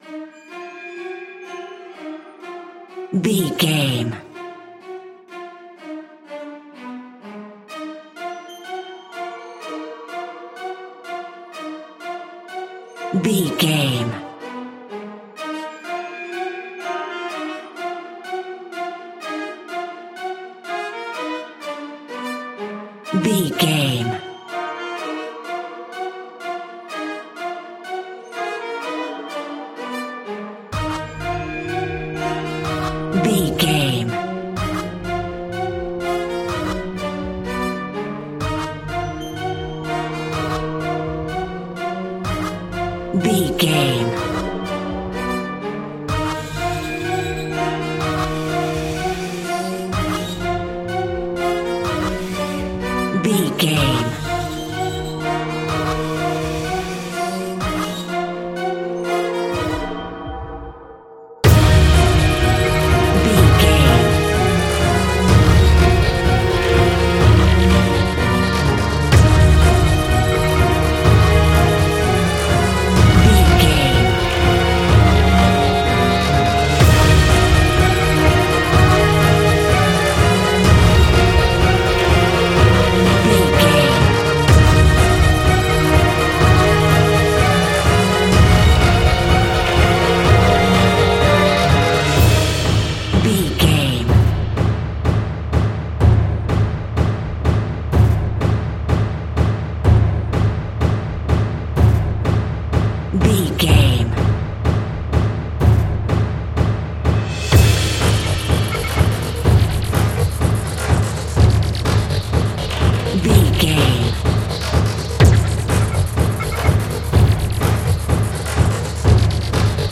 In-crescendo
Aeolian/Minor
E♭
Fast
scary
tension
ominous
dark
dramatic
driving
intense
powerful
strings
synthesiser
brass
drums
percussion
cinematic
orchestral
heroic
booming
cymbals
gongs
french horn trumpet
taiko drums
timpani